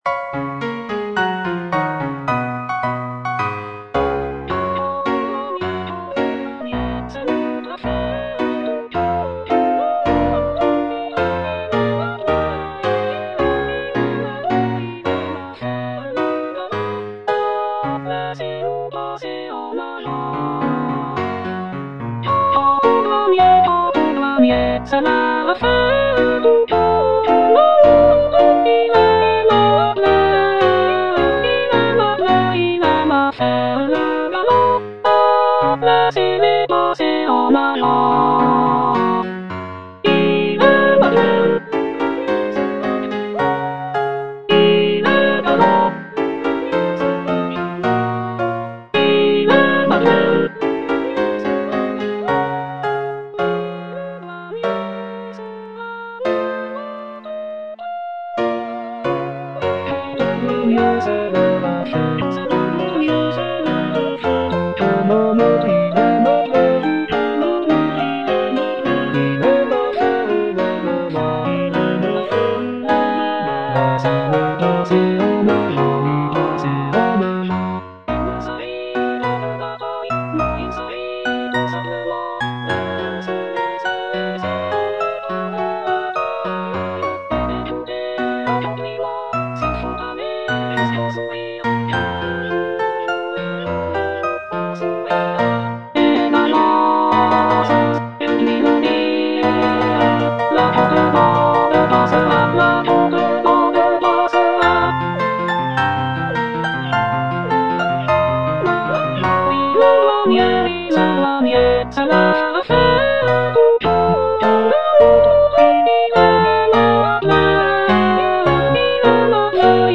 soprano III) (Emphasised voice and other voices) Ads stop